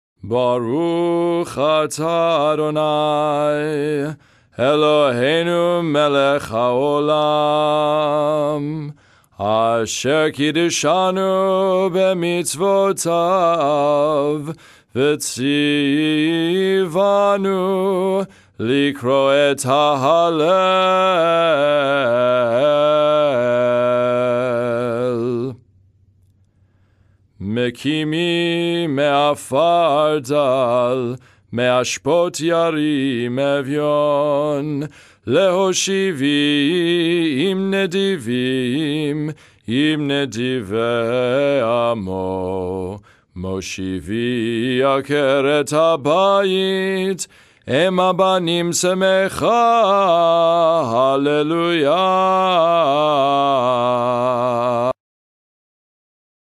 Festival Shaharit (Lower Voice)
Birkat Hallel – Psalm 113 (Nusah Ukrainian Dorian)Download
01_birkat_hallel___psalm_113__nusach_ukrainian_dorian_.mp3